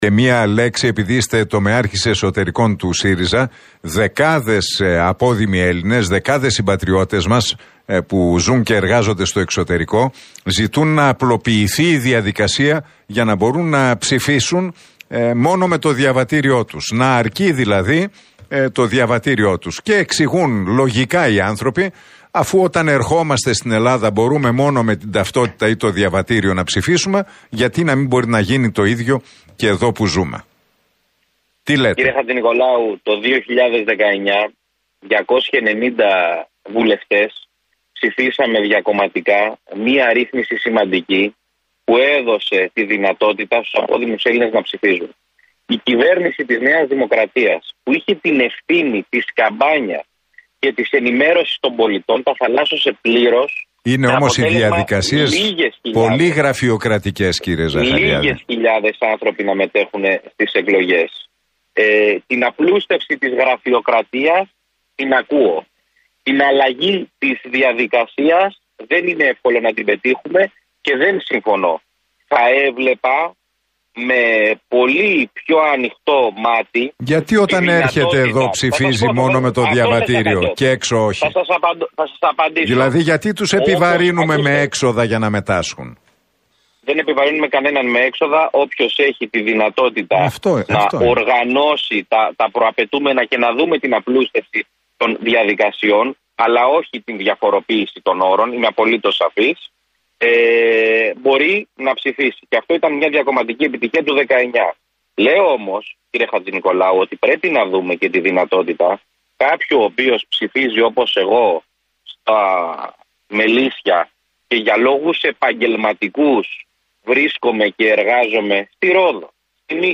Ο Κώστας Ζαχαριάδης, ερωτηθείς για το θέμα από τον Νίκο Χατζηνικολάου απάντησε πως «το 2019, 290 βουλευτές ψηφίσαμε μια σημαντική ρύθμιση που έδωσε τη δυνατότητα στους απόδημους Έλληνες να ψηφίζουν.